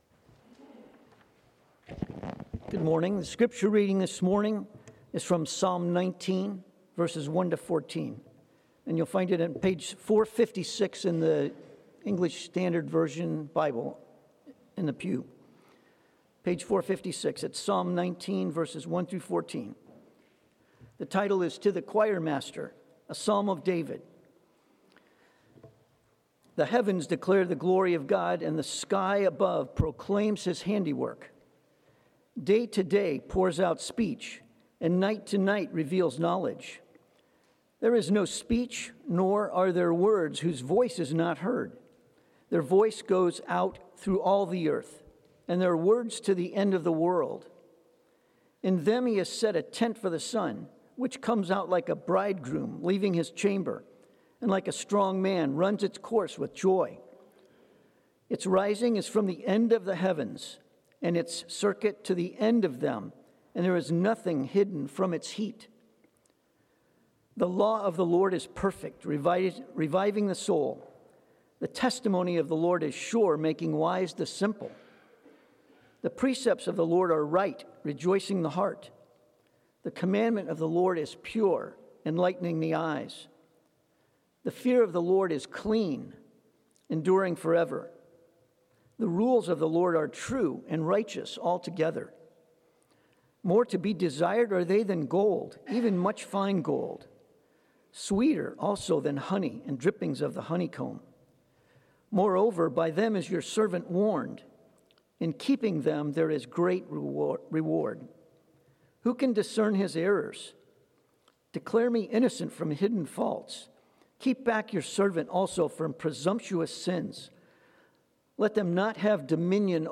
Passage: Psalm 19 Sermon